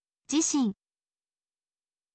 jishin